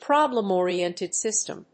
意味・対訳 プロブレムオリエンティッドシステム